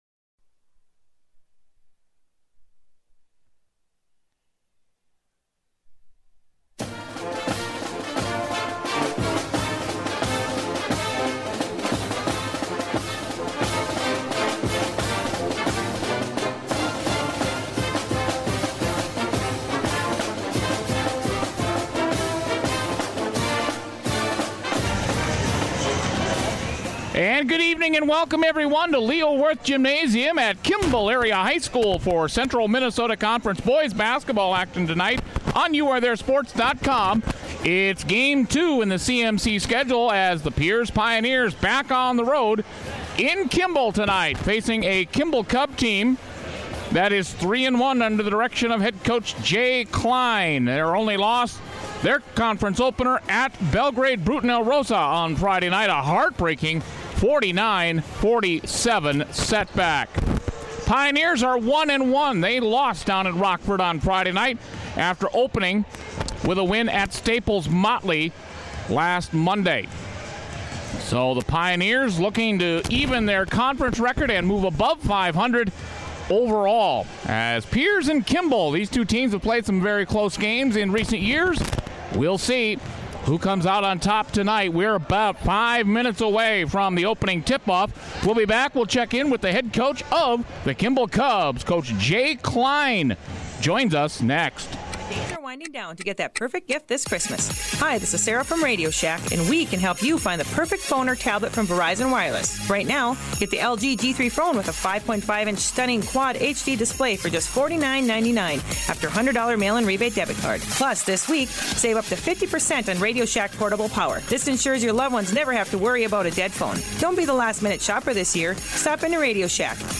12/9/14 Pierz vs Kimball Boys Basketball